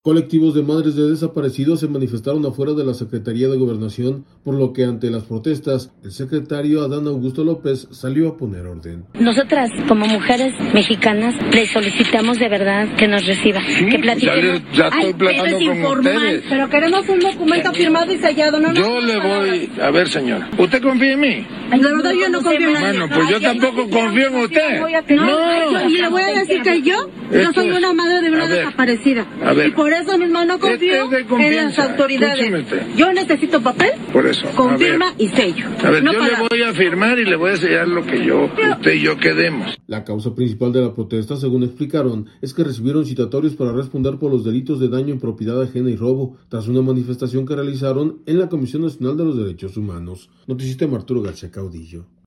audio Colectivos de madres de desaparecidos se manifestaron afuera de la Secretaría de Gobernación, por lo que, ante las protestas, el secretario Adán Augusto López, salió a poner orden.